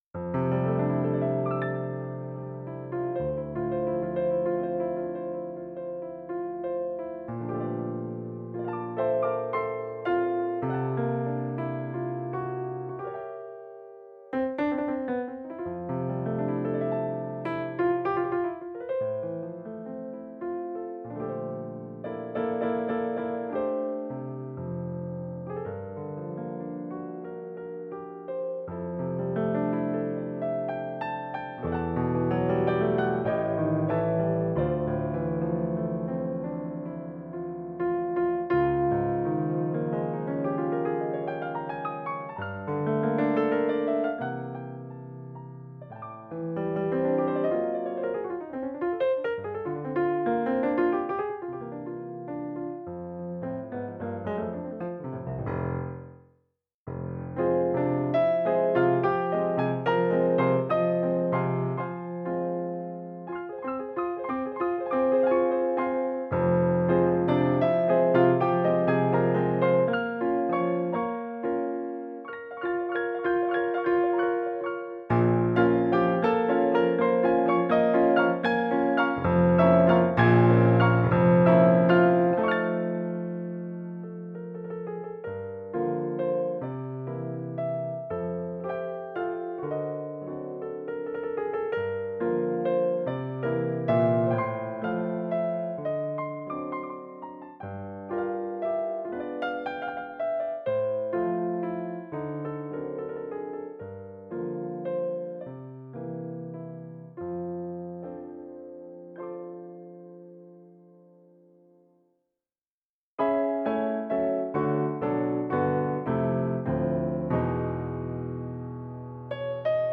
ly-GEM RP910 Steinway.mp3